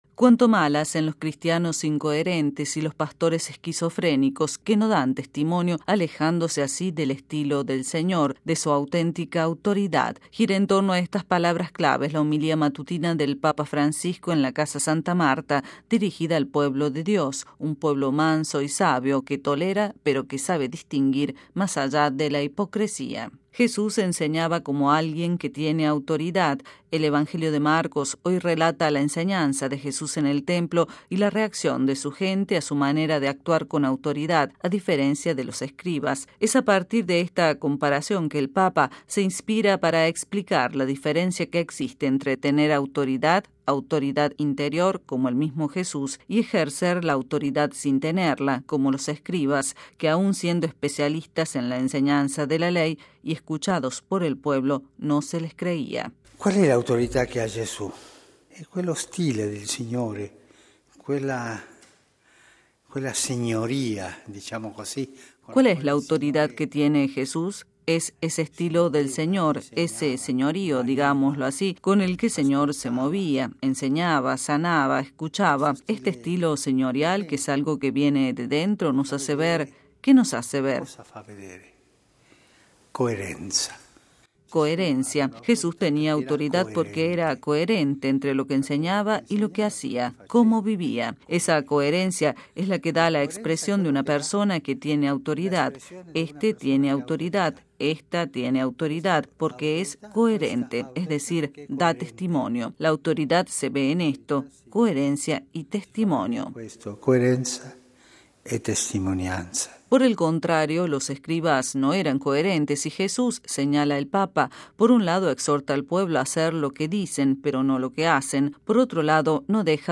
HomilíaMisa en Santa MartaPapa Francisco
Escuche el servicio con la voz del Papa
Audio-misa-Santa-Marta.mp3